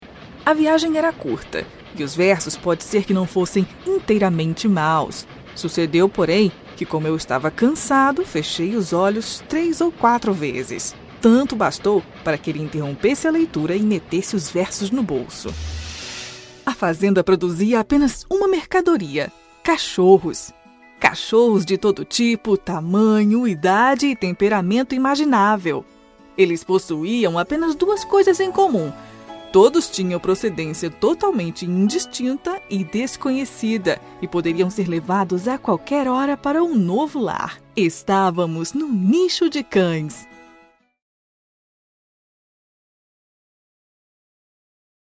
PT BR LC AB 01 Audiobooks Female Portuguese(Brazilian)